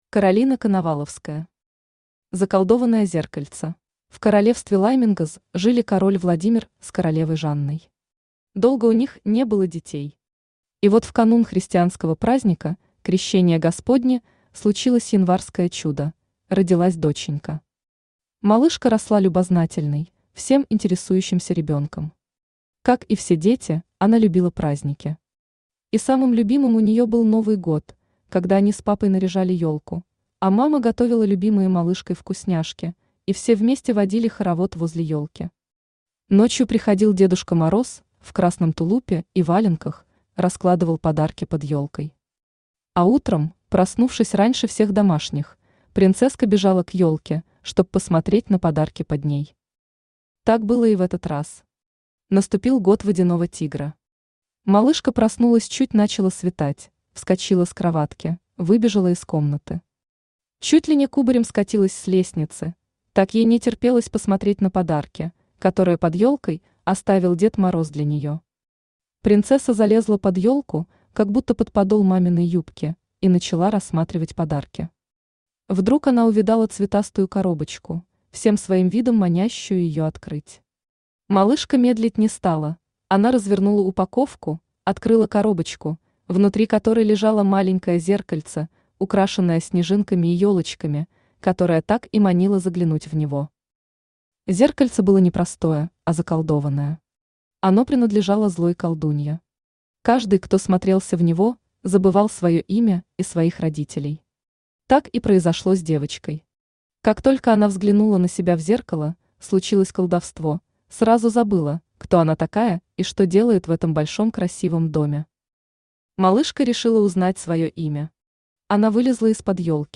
Aудиокнига Заколдованное зеркальце Автор Каролина Коноваловская Читает аудиокнигу Авточтец ЛитРес.